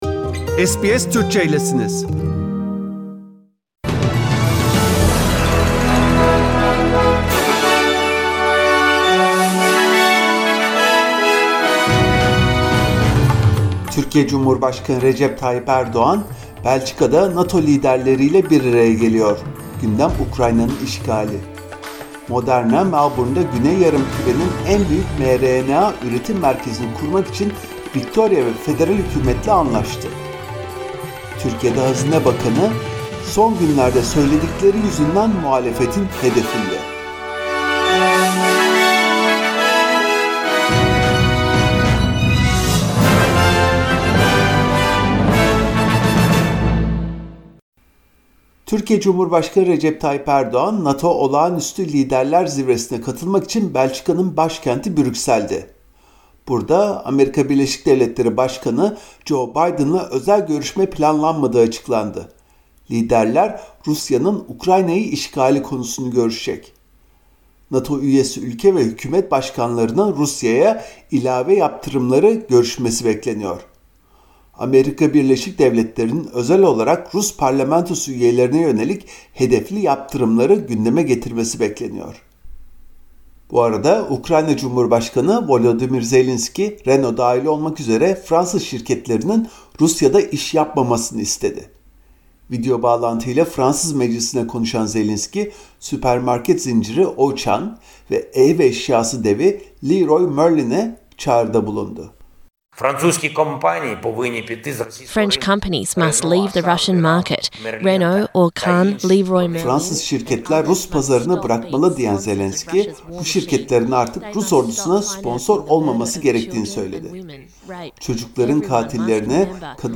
SBS Türkçe’den Avustralya, Türkiye ve dünyadan haberler.